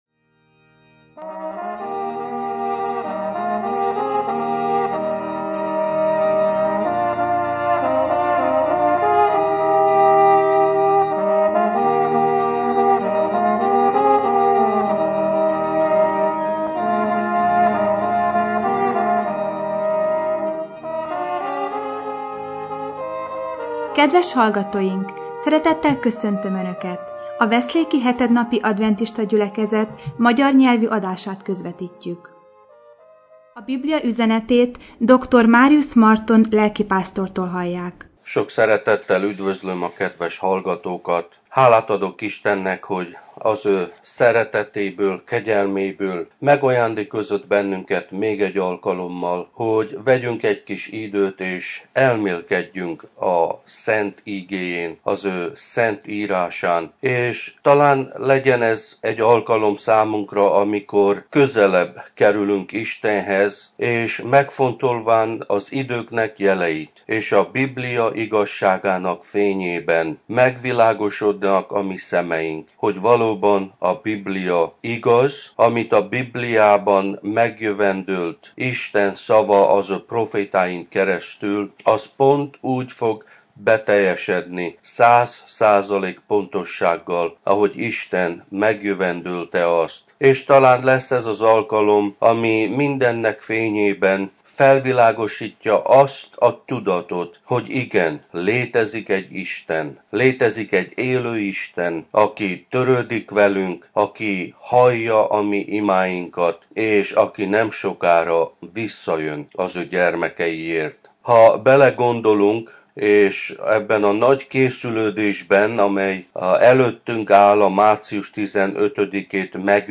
Igét hirdet